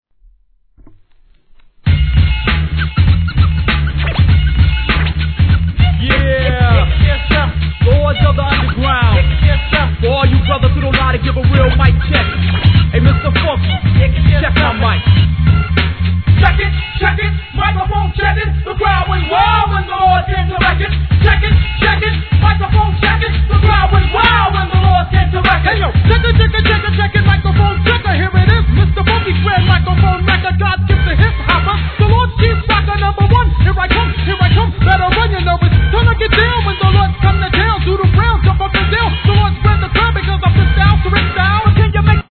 HIP HOP/R&B
ミドルサウンドど真ん中！